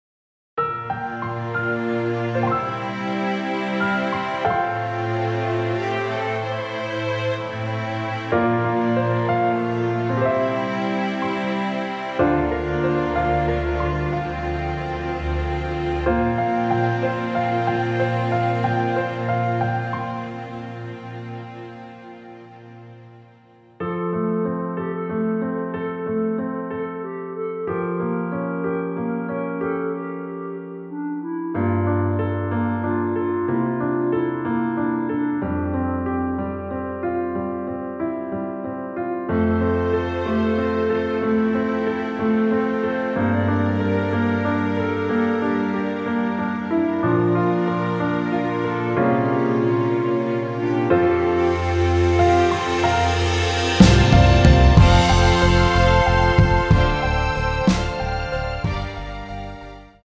원곡보다 짧은 MR입니다.(아래 재생시간 확인)
원키에서(+4)올린 (짧은편곡)멜로디 포함된 MR입니다.
앞부분30초, 뒷부분30초씩 편집해서 올려 드리고 있습니다.